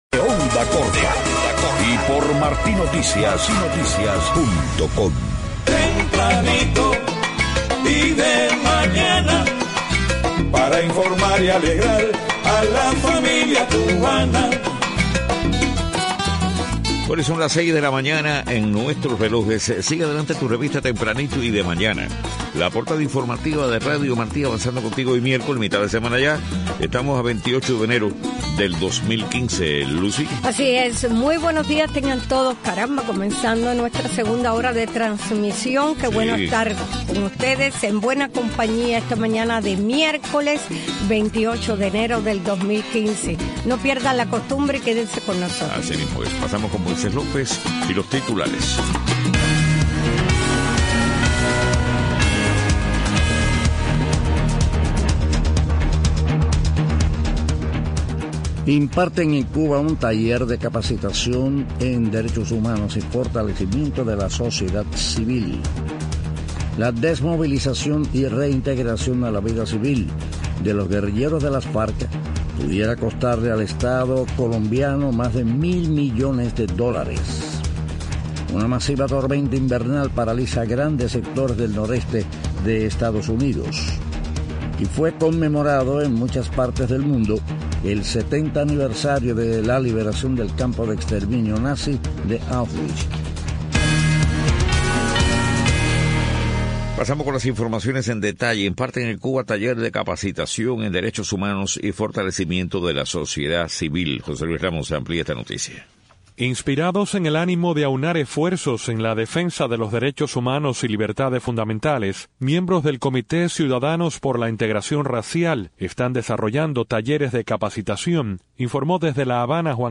6:00 a.m. Noticias: Imparten en Cuba taller de capacitación en derechos humanos y fortalecimiento de la sociedad civil.